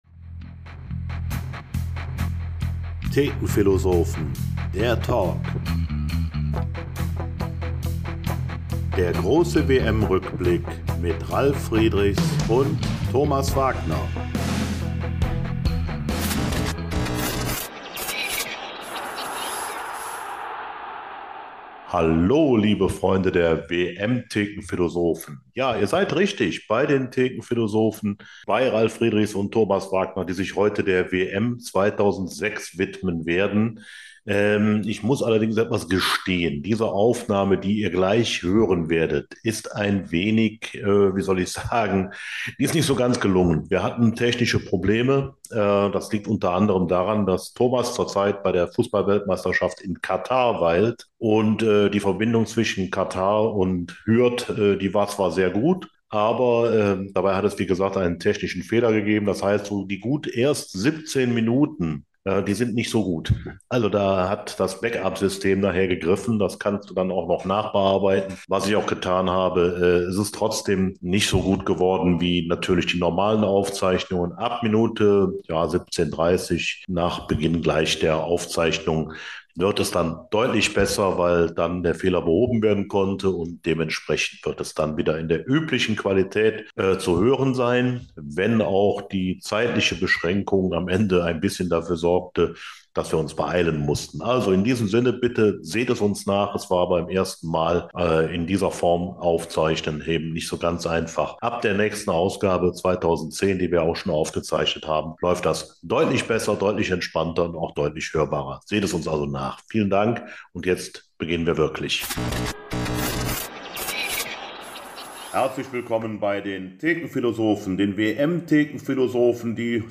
Wichtig: Diese Ausgabe wurde via Zoom aus Katar aufgezeichnet, zu Beginn gab es technische Probleme, die im weiter Verlauf behoben wurden.